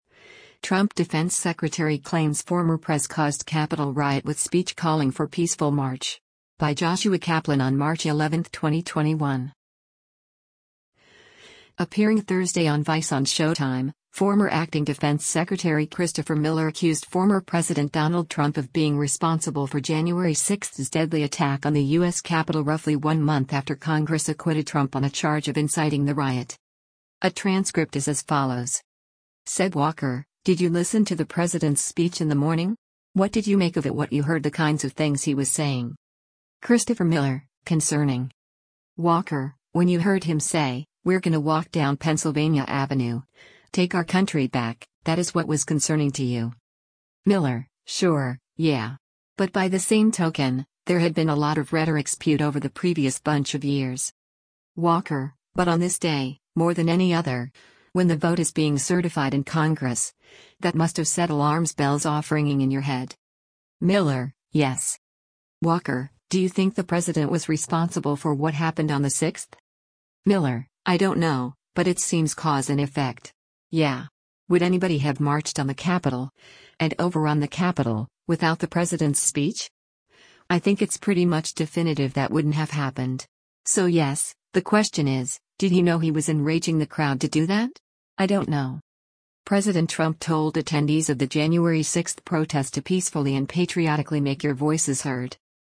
Appearing Thursday on VICE on Showtime, former acting Defense Secretary Christopher Miller accused former President Donald Trump of being responsible for Jan. 6’s deadly attack on the U.S. Capitol roughly one month after Congress acquitted Trump on a charge of inciting the riot.